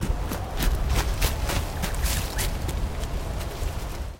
Звуки включают крики, шум шагов и другие характерные для эму аудиоэффекты.
звук бегущего эму